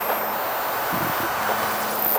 nerfs_psynoise10.ogg